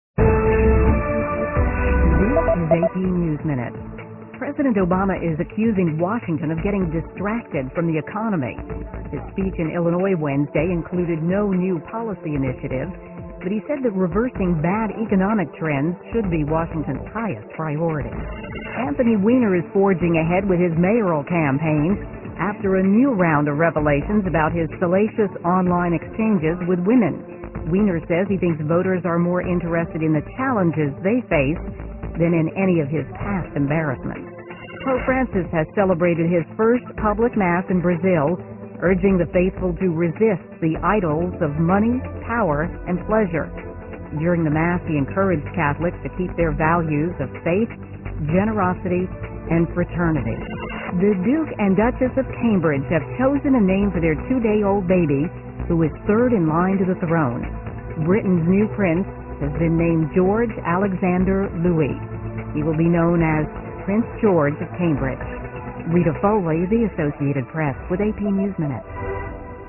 在线英语听力室美联社新闻一分钟 AP 2013-07-29的听力文件下载,美联社新闻一分钟2013,英语听力,英语新闻,英语MP3 由美联社编辑的一分钟国际电视新闻，报道每天发生的重大国际事件。电视新闻片长一分钟，一般包括五个小段，简明扼要，语言规范，便于大家快速了解世界大事。